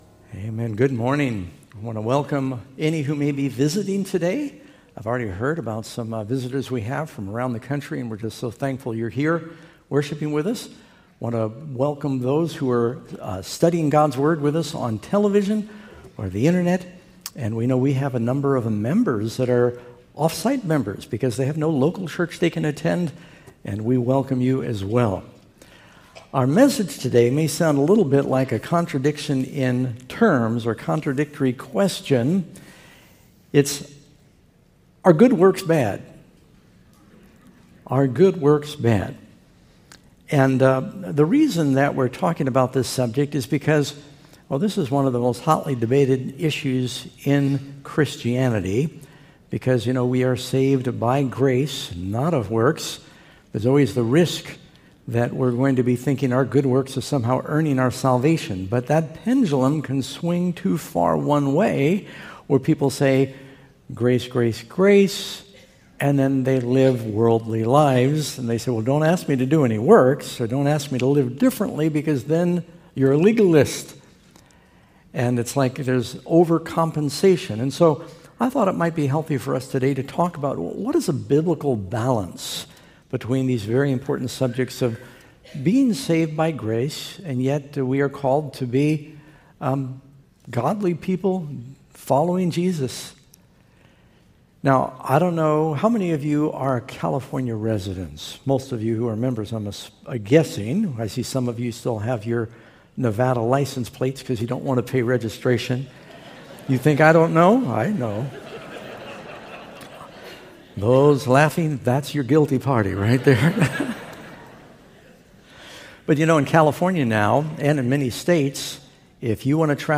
Granite Bay SDA Church